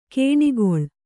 ♪ kēṇigoḷ